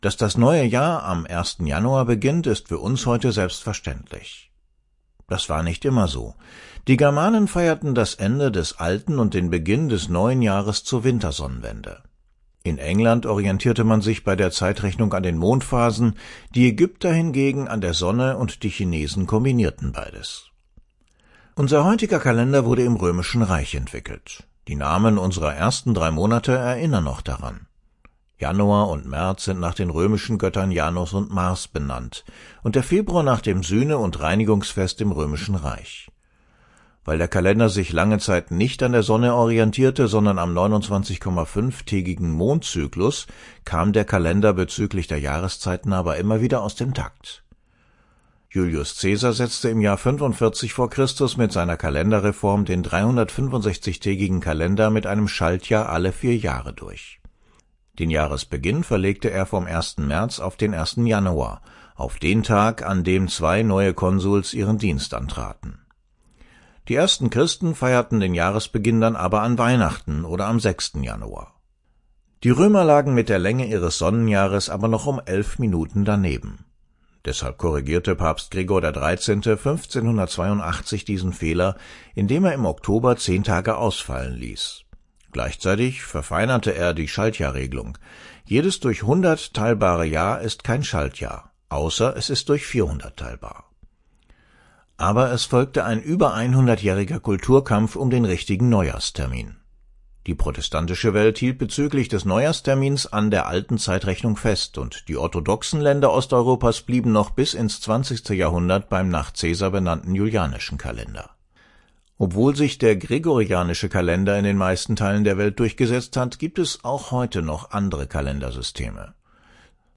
Diesen Radiobeitrag